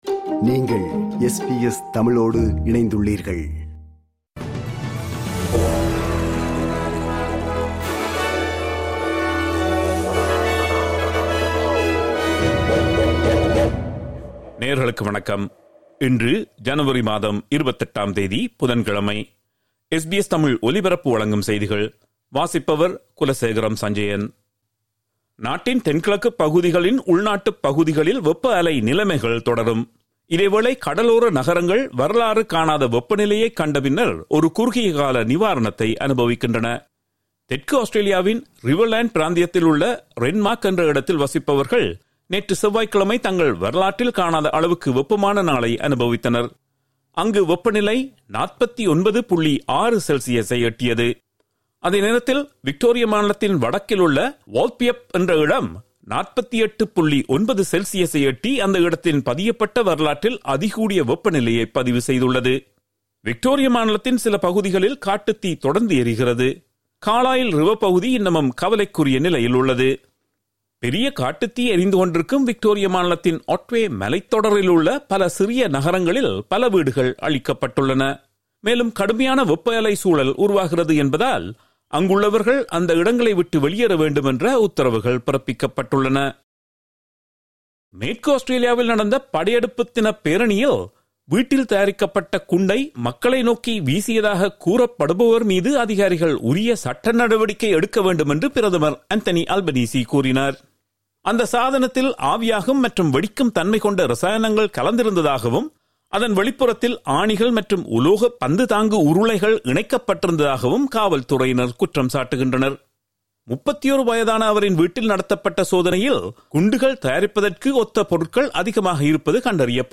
இன்றைய செய்திகள்: 28 ஜனவரி 2026 - புதன்கிழமை
SBS தமிழ் ஒலிபரப்பின் இன்றைய (புதன்கிழமை 28/01/2026) செய்திகள்.